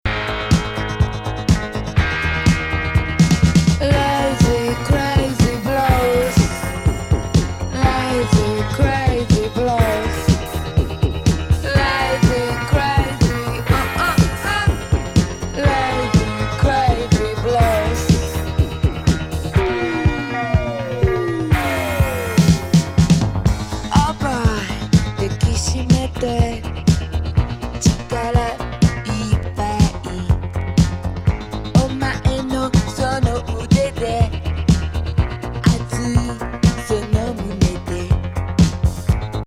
胸キュン・ロック名曲！